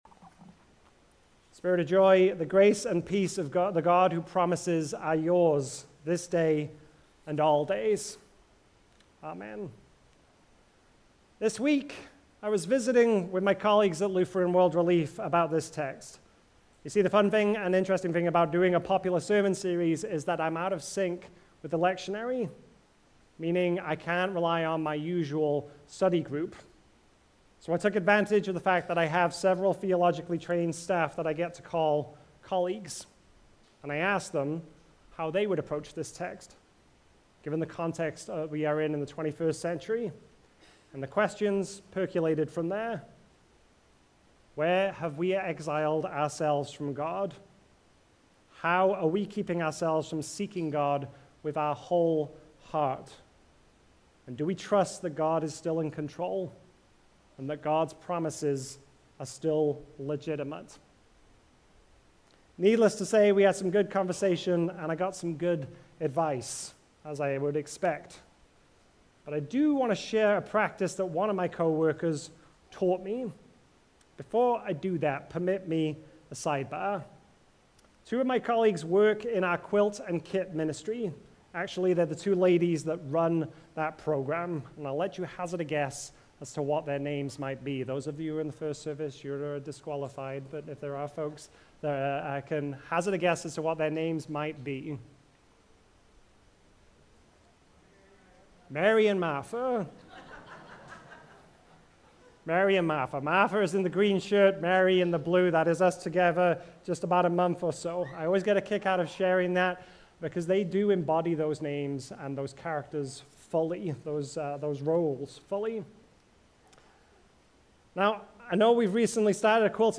9.14.25-Sermon.mp3